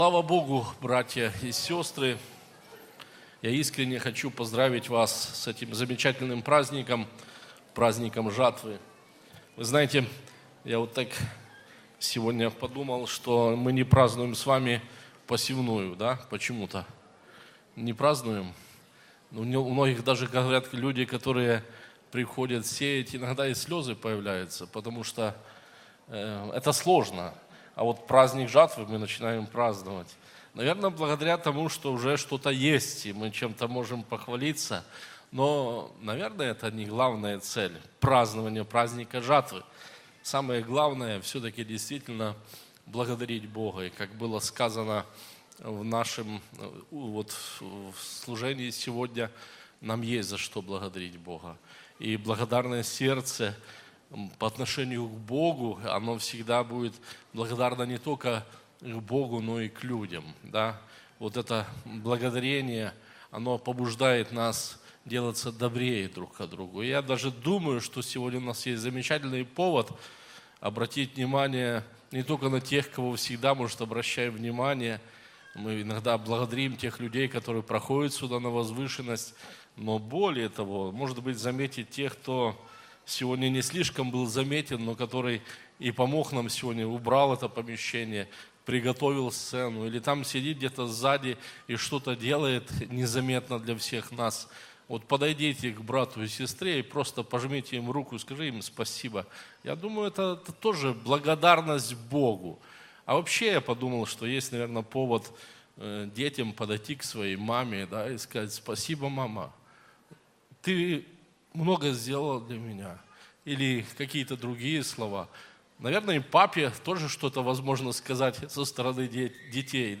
Church4u - Проповеди